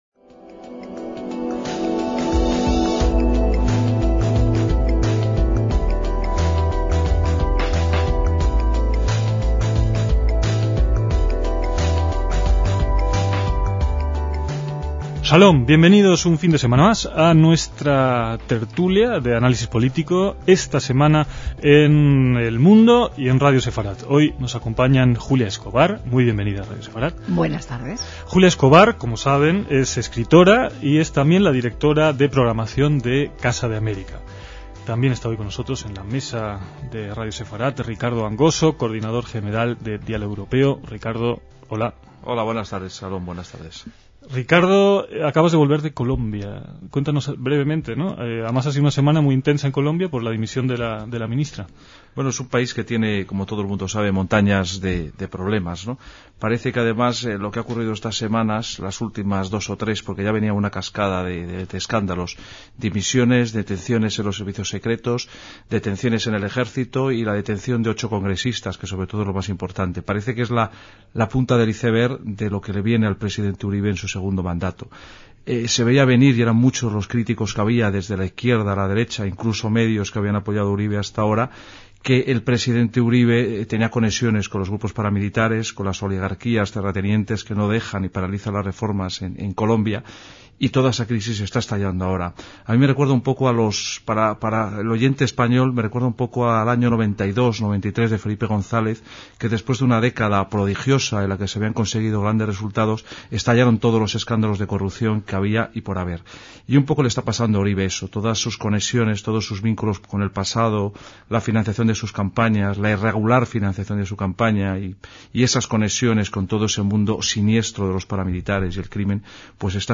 DECÍAMOS AYER (24/2/2007) - En la mesa de debate que reponemos aquí destacan dos temas: uno la Casa o Centro Sefarad Israel recién inaugurada/o, y dos, las misiones de paz o guerra (Irak y Afganistán).